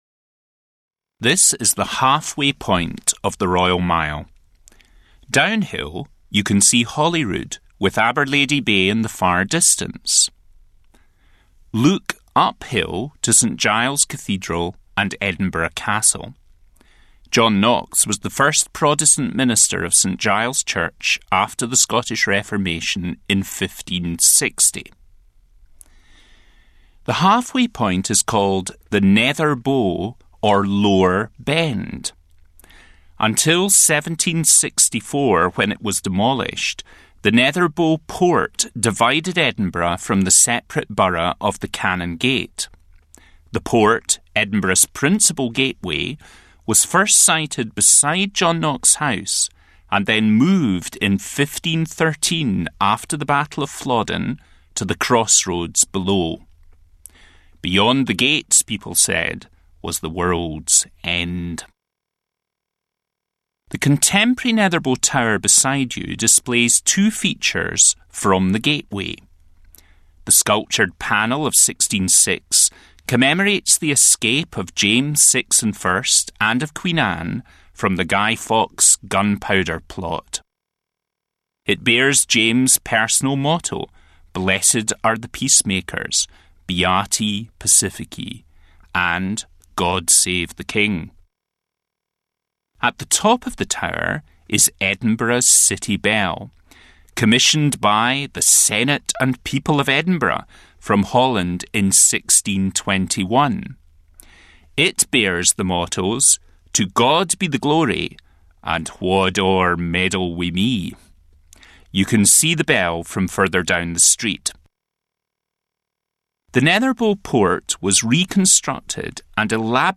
John Knox House Audio Tour